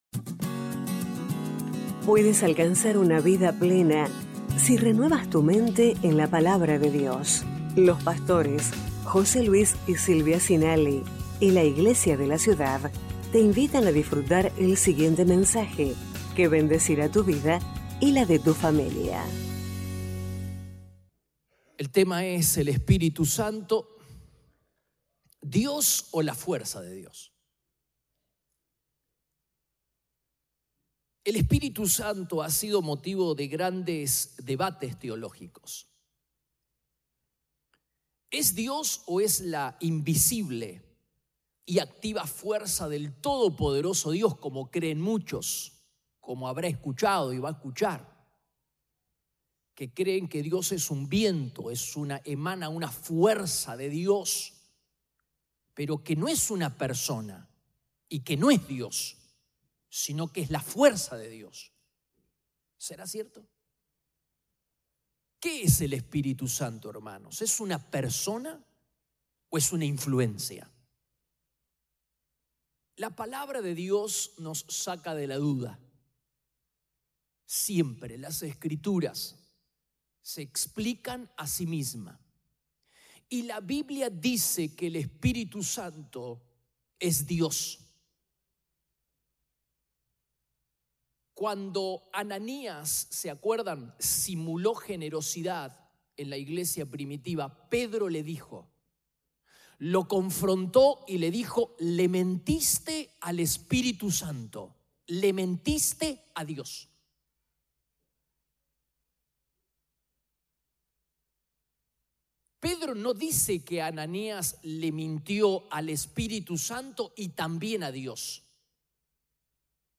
Iglesia de la Ciudad - Mensajes / El Espíritu Santo, ¿Dios o la fuerza de Dios?